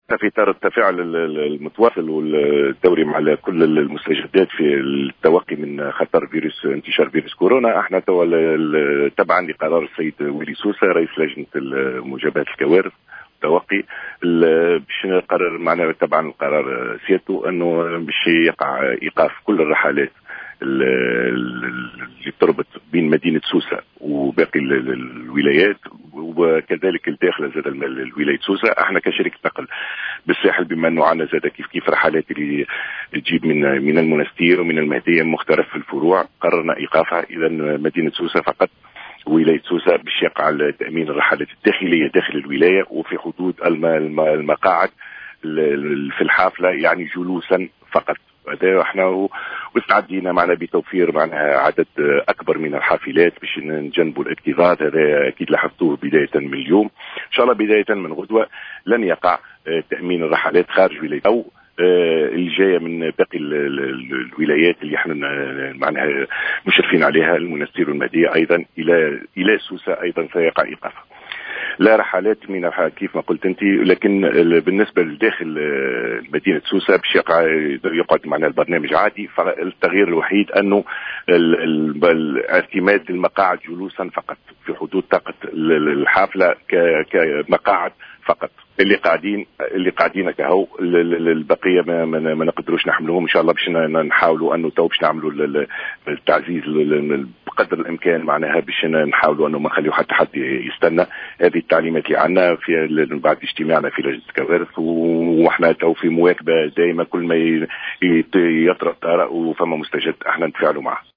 وأبرز في هذا الإطار، في تصريح للجوهرة أف أم، أن الشركة ستستغل عددا إضافيا من الحافلات تجنبا للاكتظاظ، على أن تتواصل الرحلات الداخلية وفقا للروزنامة العادية. وستدخل هذه الإجراءات التي تأتي في إطار التوقي من انتشار فيروس كورونا المستجد، حيّز التنفيذ بداية من يوم الجمعة 20 مارس 2020.